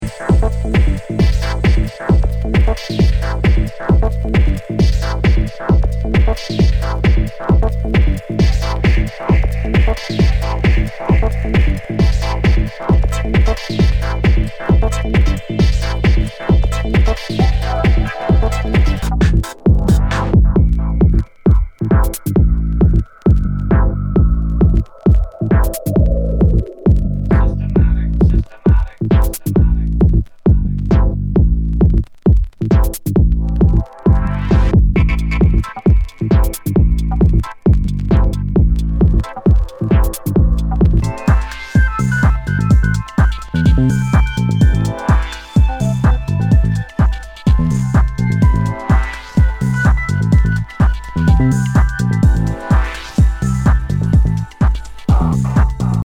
HOUSE/TECHNO/ELECTRO
ナイス！ディープ・テック・ハウス！
全体にチリノイズが入ります